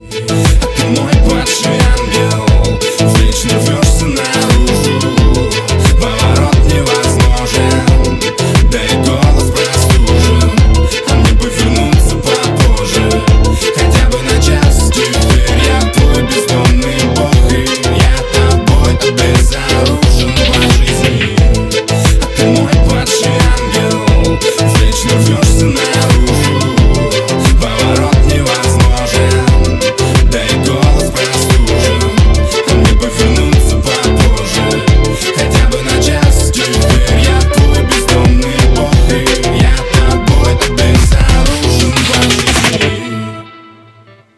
• Качество: 160, Stereo
ритмичные
русский рэп
качающие